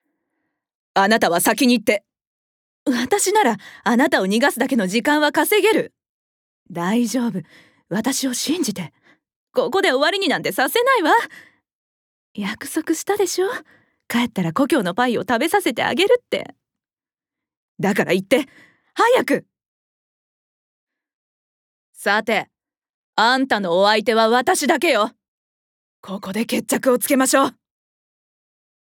ボイスサンプル
素直になれないお嬢様
大事な人を庇う女性